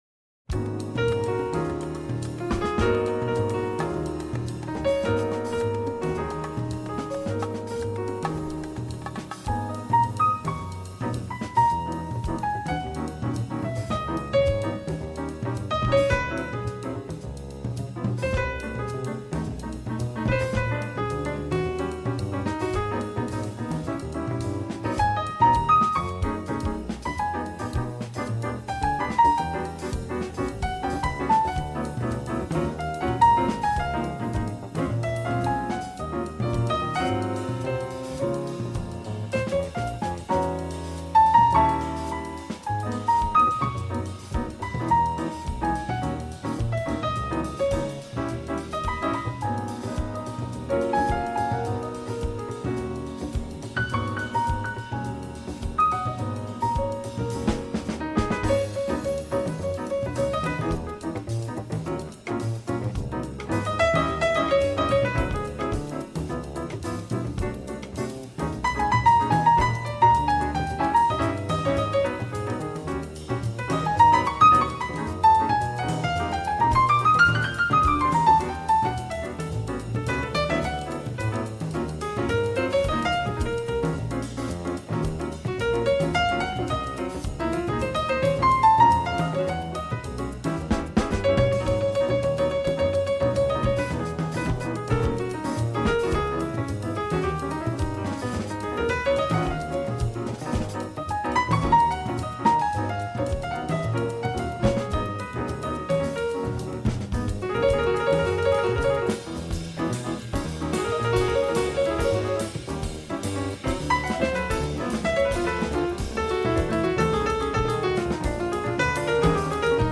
Per celebrar-ho he portat a la festa a 10 intèrprets que, crec, encara no han sortit al programa.